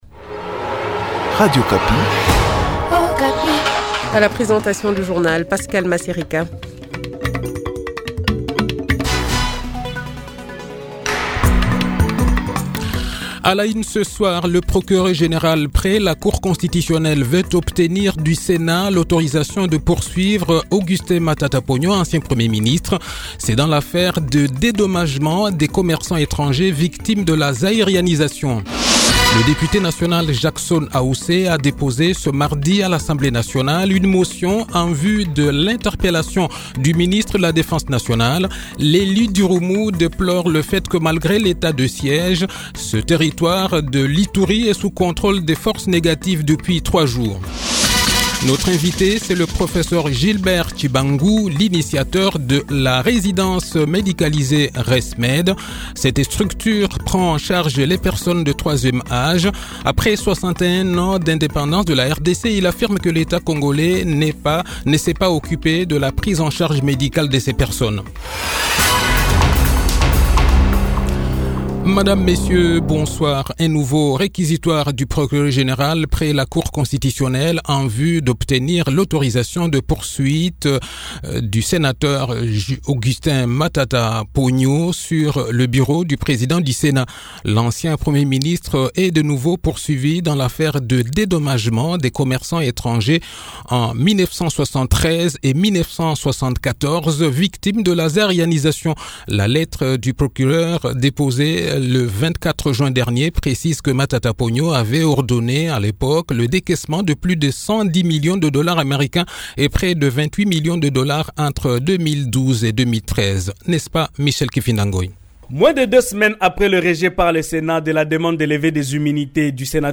Le journal de 18 h, 29 Juin 2021